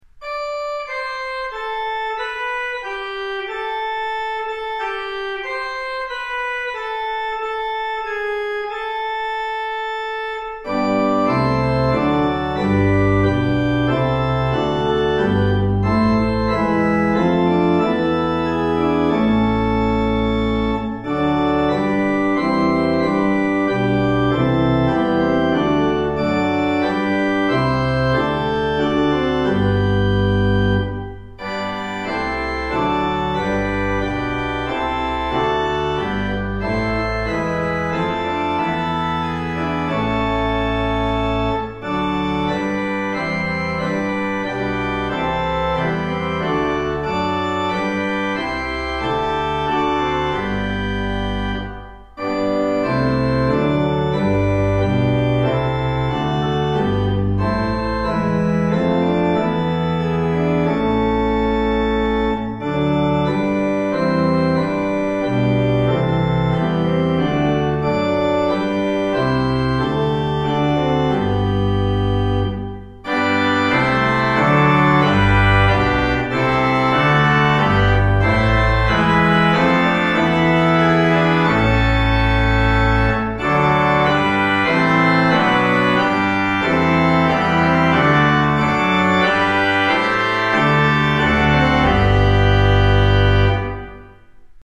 Organ: Little Waldingfield